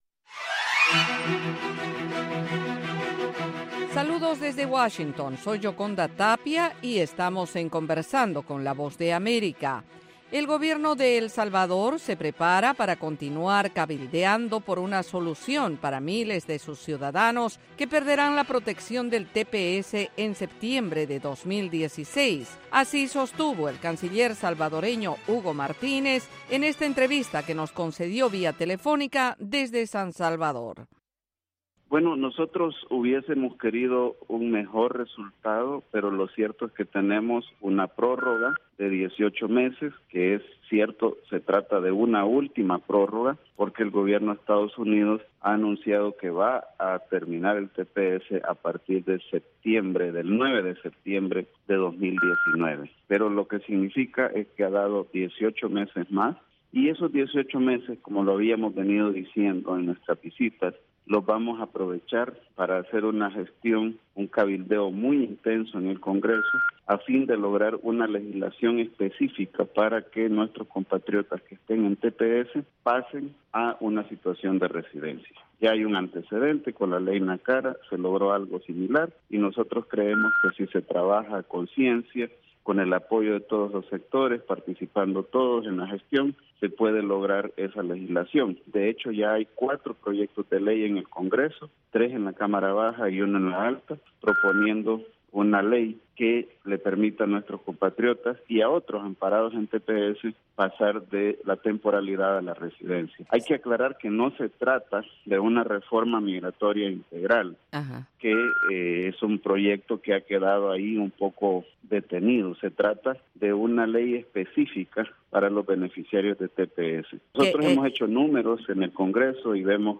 La Voz de América entrevista, en cinco minutos, a expertos en diversos temas.